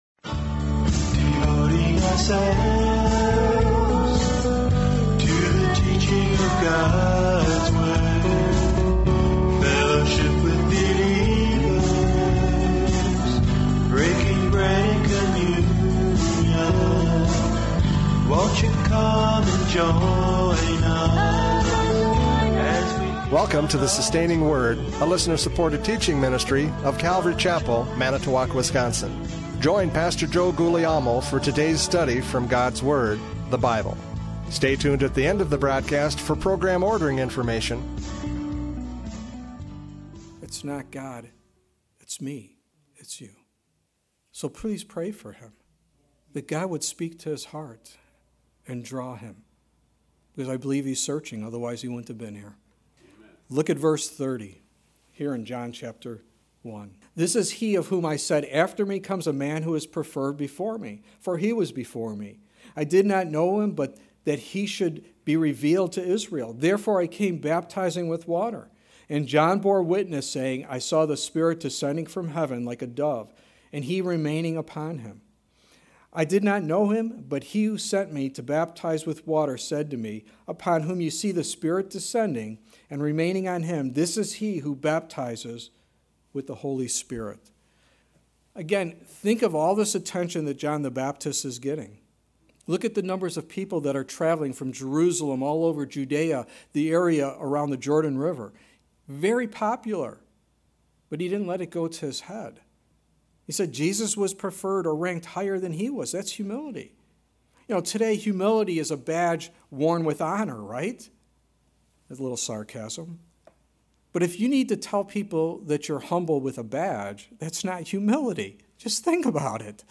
John 1:29-34 Service Type: Radio Programs « John 1:29-34 John the Baptist’s Testimony!